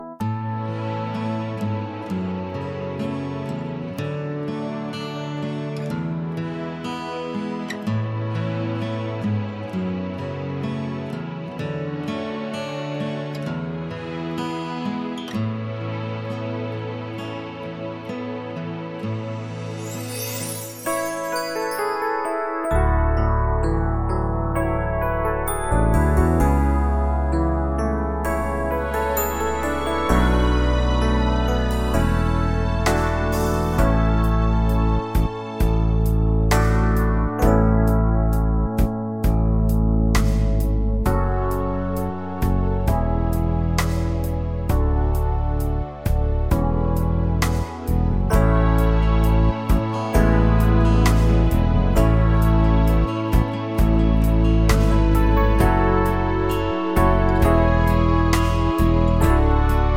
no sax Pop (1980s) 4:34 Buy £1.50